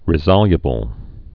(rĭ-zŏlyə-bəl)